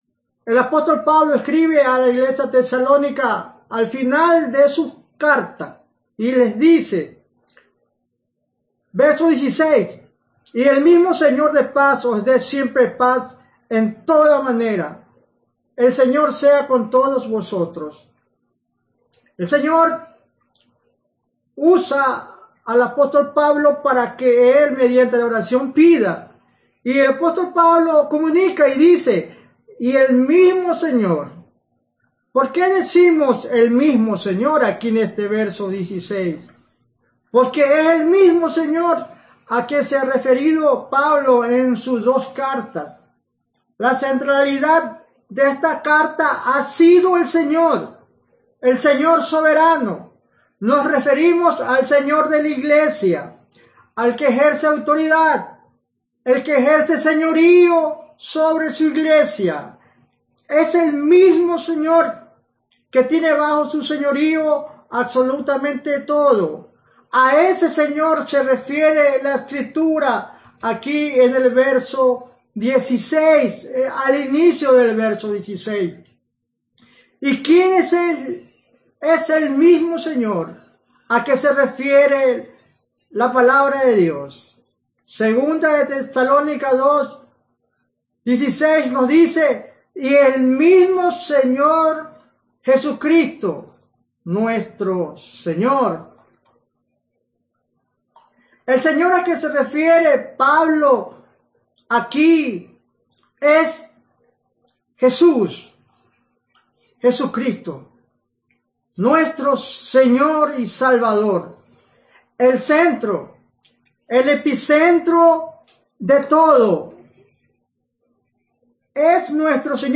Audio del sermón